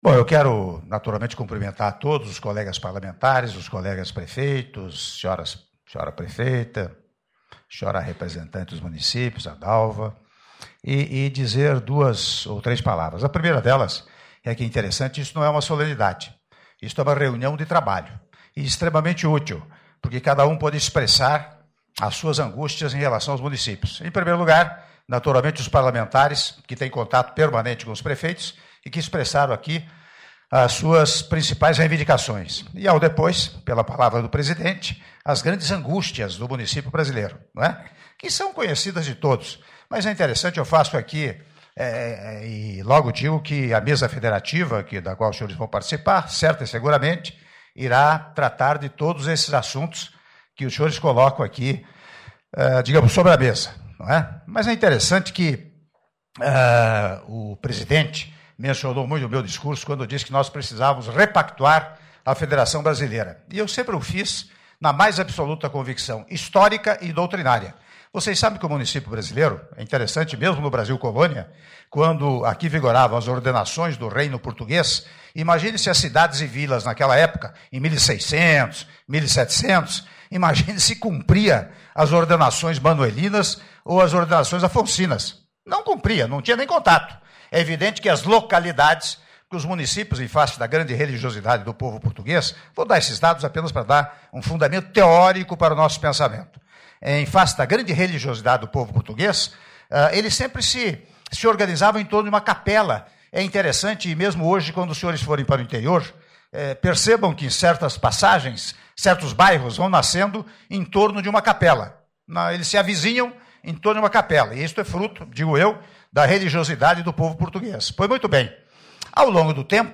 Áudio do discurso do presidente da República em exercício, Michel Temer, durante a reunião da Confederação Nacional de Municípios (07min49s) - Brasília/DF